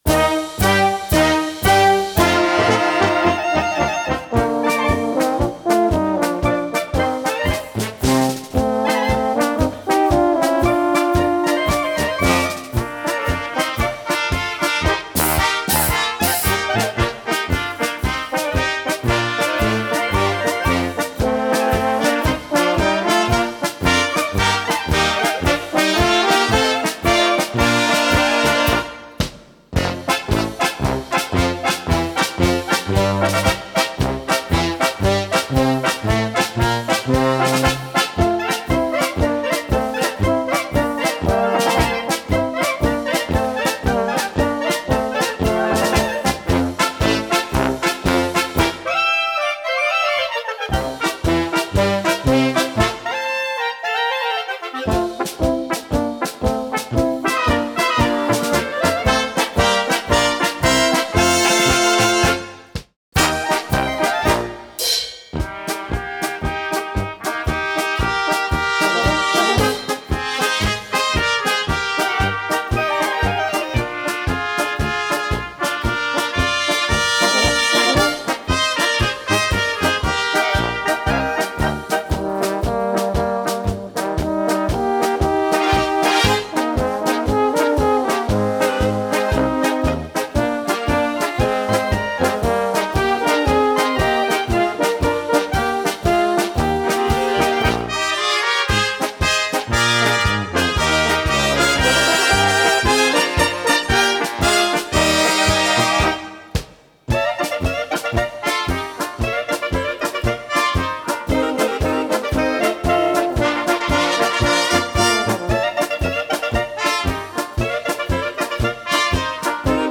für Blaskapelle